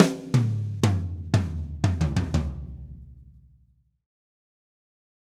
Drumset Fill 15.wav